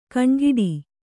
♪ kaṇgiḍi